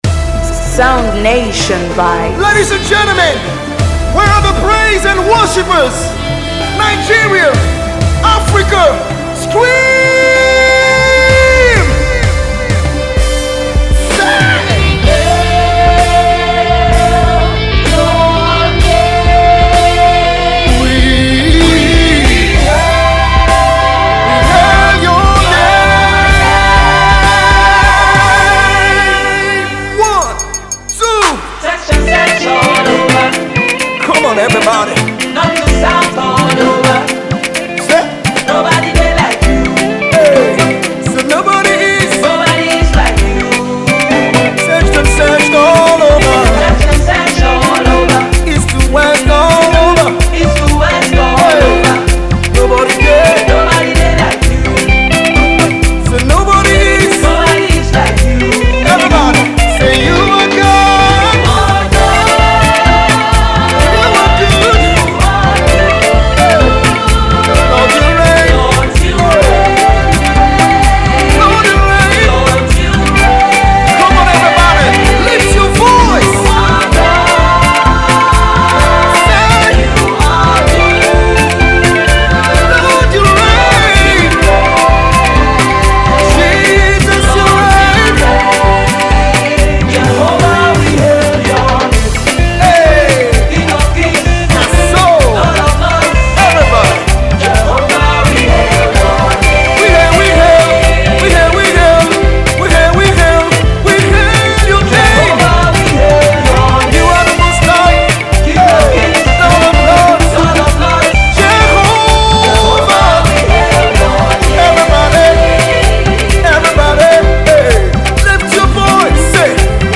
It is refreshing. Its a joy booster.